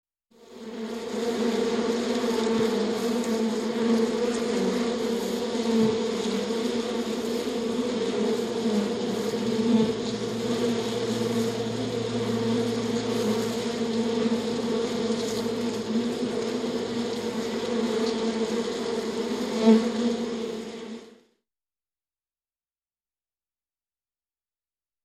Жужжание пчел в звуках улья